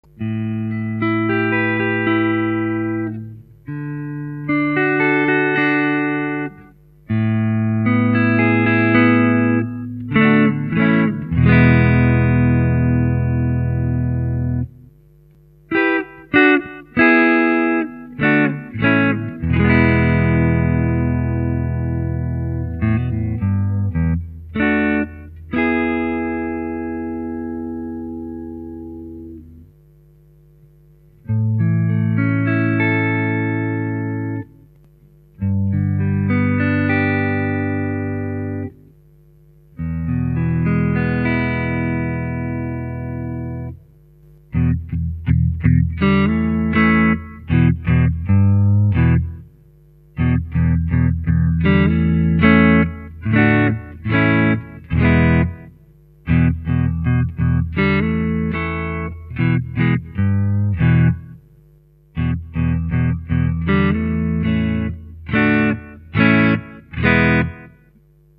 A classic P-90, with traditional P-90 sound.
The Unicorn is vintage wound but at the fatter end of the vintage range.
Listen here:     Bridge Full      Bridge Tapped
Magnet Alnico 2